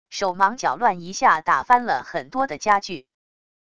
手忙脚乱一下打翻了很多的家具wav音频